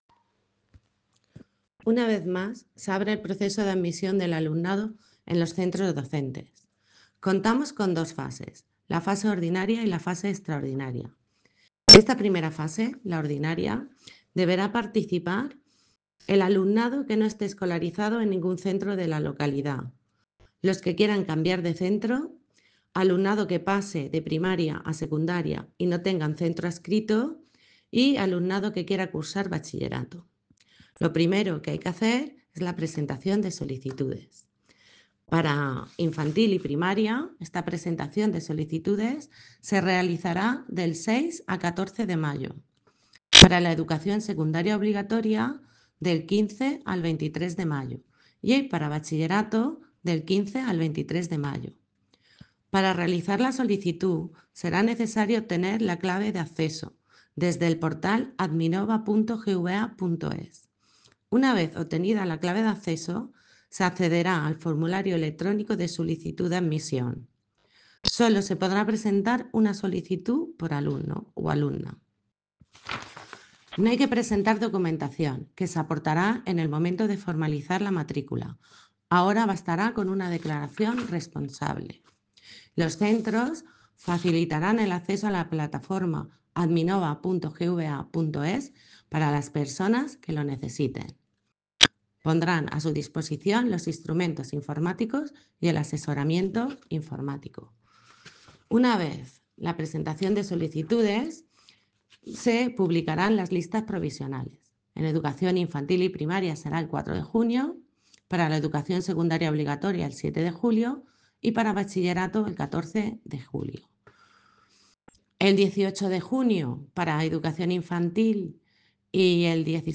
audio concejala de Educación.
audio-concejala-de-Educacion.-Inicio-periodo-matriculacion-escolar.mp3